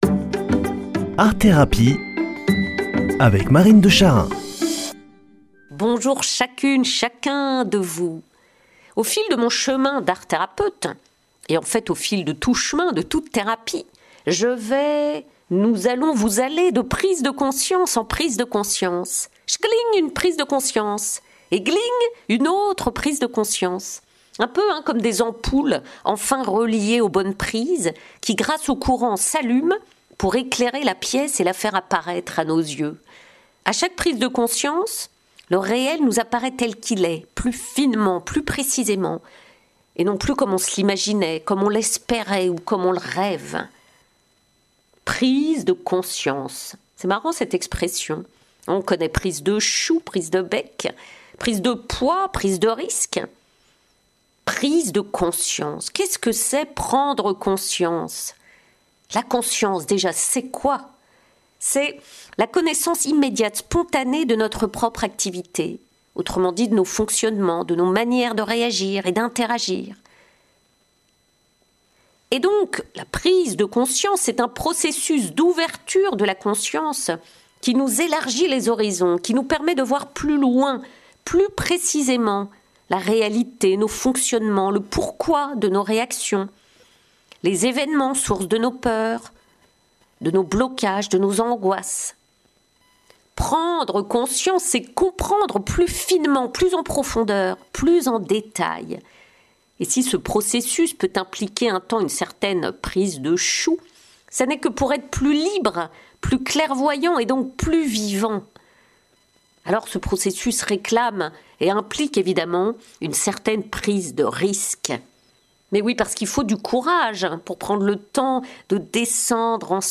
Présentatrice